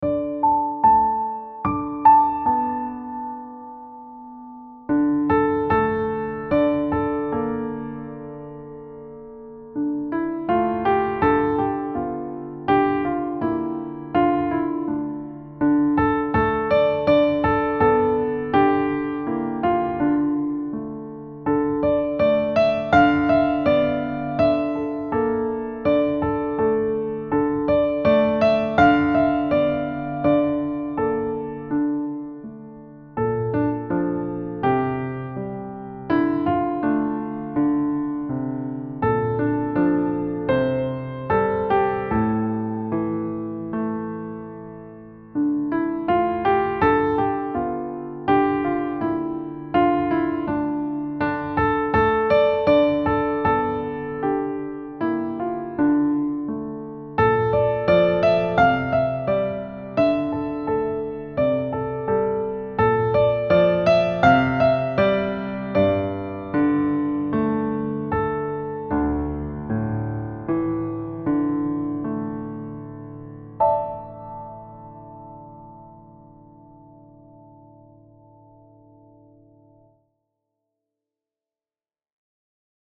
Time Signature: 4/4